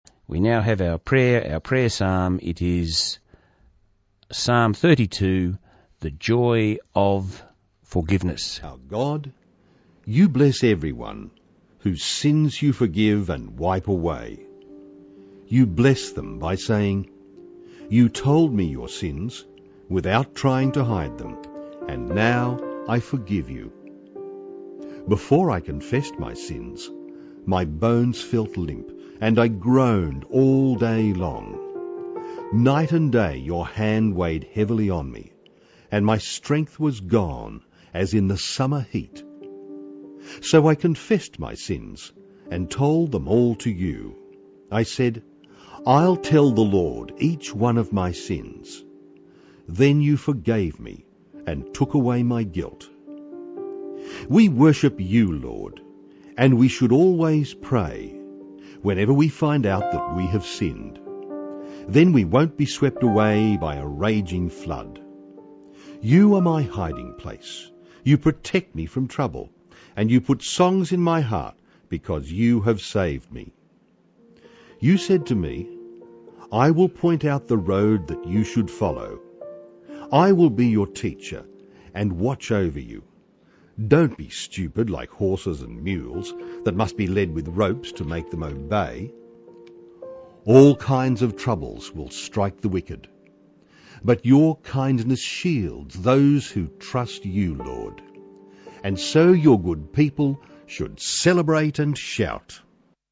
Top prayer for 2014
Its entitled “The joy of forgiveness” and is Psalm 32 (CEV). This was broadcast on 4 May 2014 during the “Songs of Hope” program.